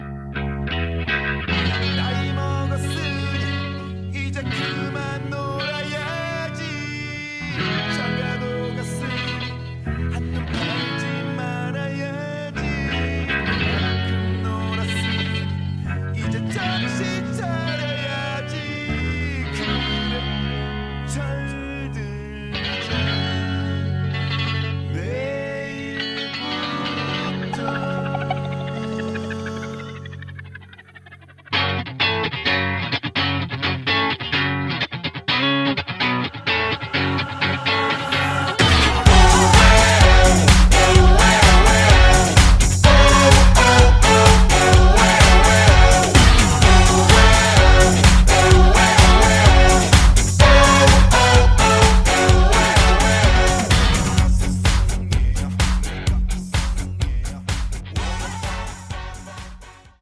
음정 -1키 3:45
장르 가요 구분 Voice MR